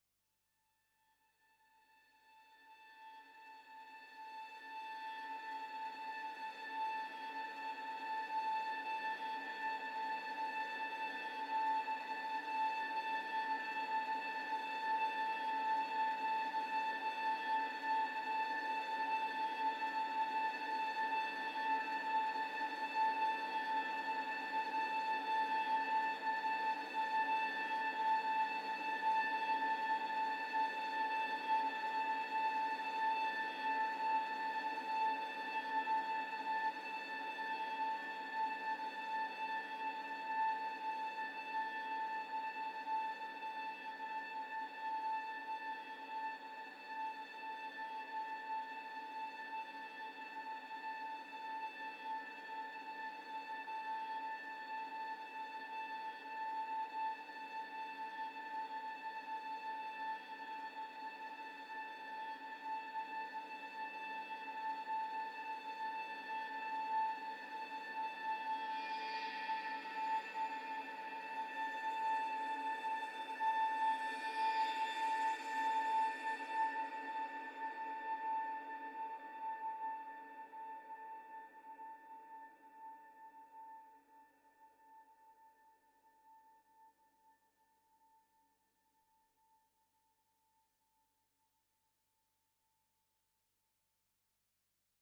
Eerie violin provides monotone tension and suspense.